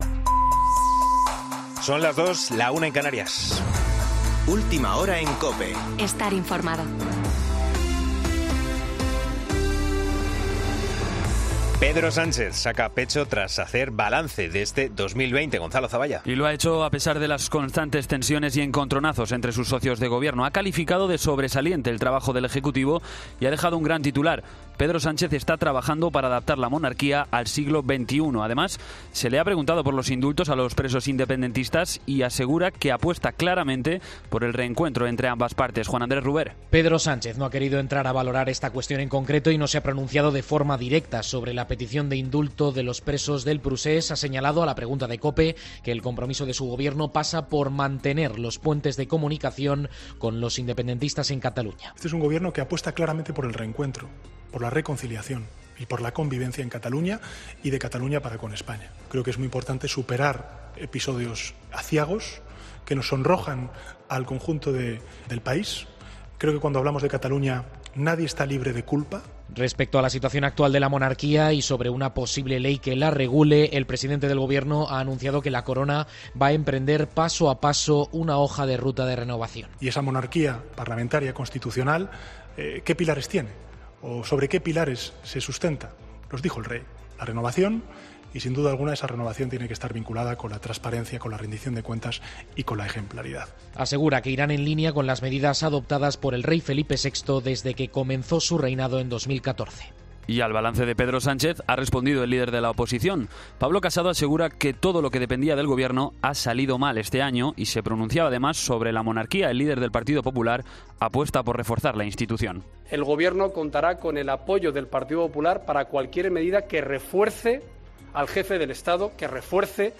Boletín de noticias COPE del 30 de diciembre de 2020 a las 02.00 horas